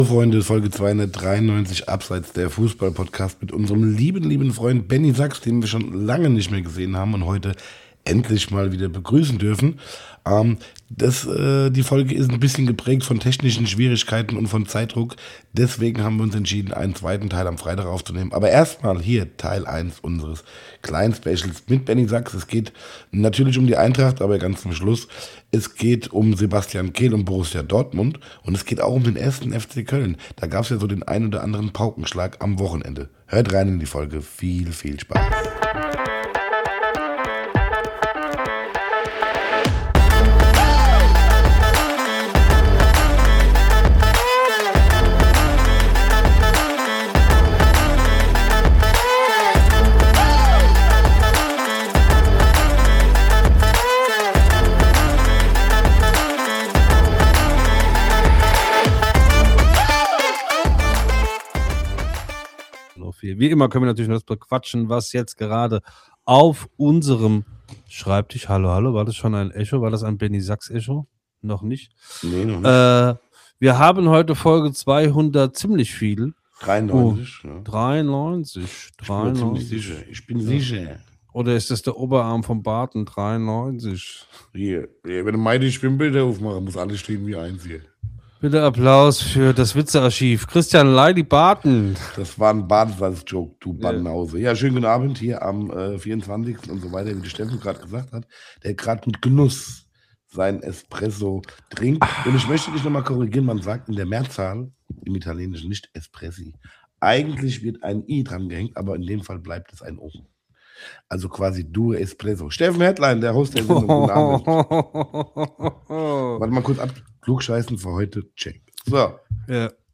Leider hatten wir in dieser Folge technische Probleme, aber so ist das manchmal bei den Schönen und Reichen xD Mehr